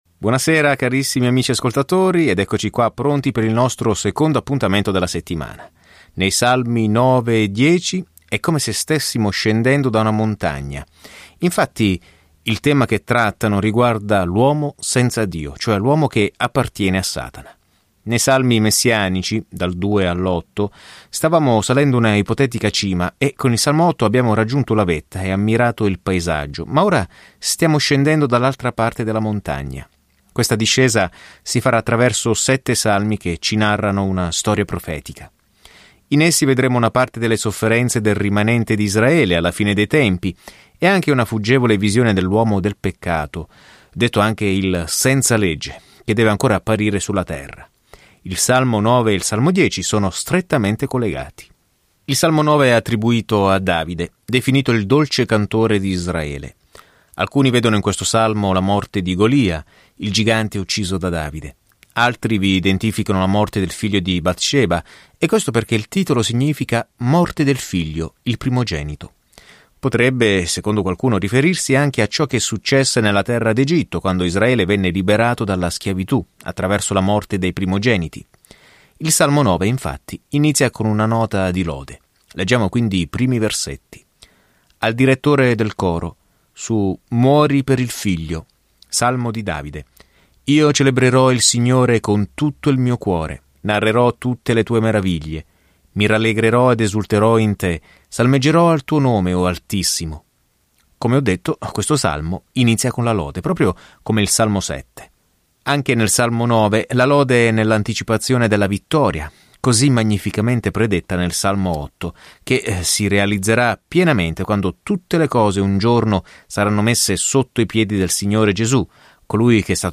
Viaggia ogni giorno attraverso i Salmi mentre ascolti lo studio audio e leggi versetti selezionati della parola di Dio.